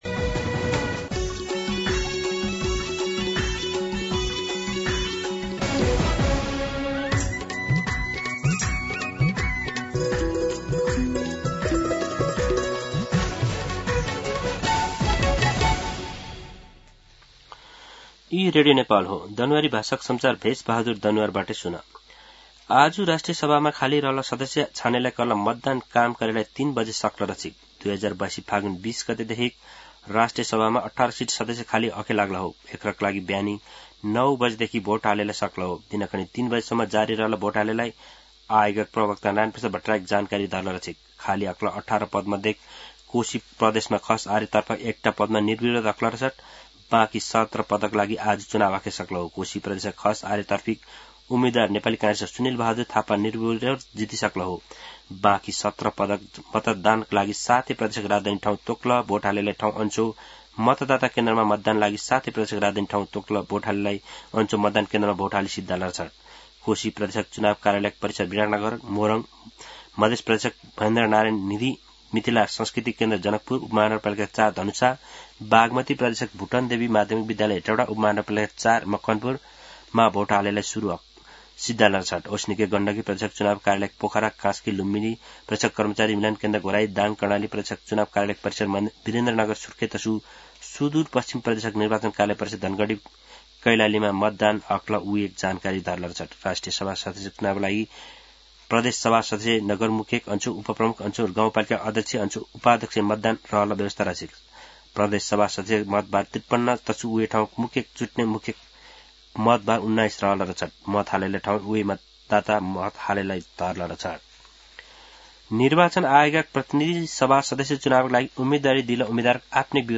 दनुवार भाषामा समाचार : ११ माघ , २०८२